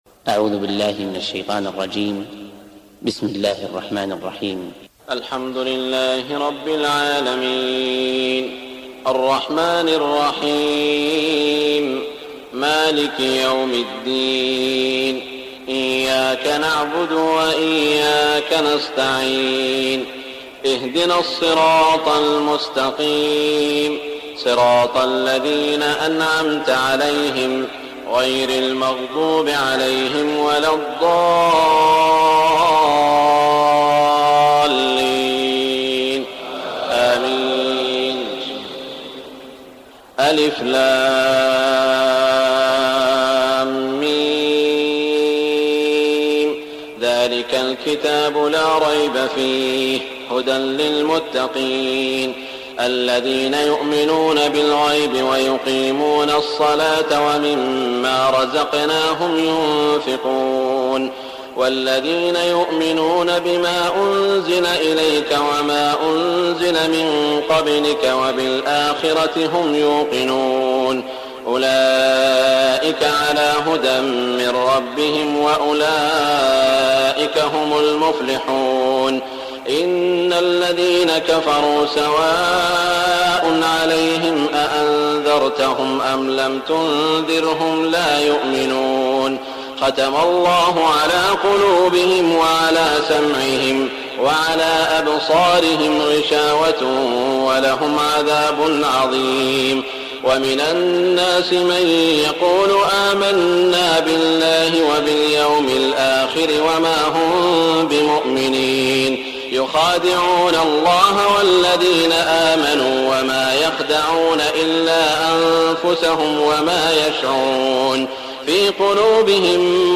تهجد ليلة 21 رمضان 1418هـ من سورة البقرة (1-91) Tahajjud 21 st night Ramadan 1418H from Surah Al-Baqara > تراويح الحرم المكي عام 1418 🕋 > التراويح - تلاوات الحرمين